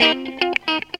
GTR 103 CM.wav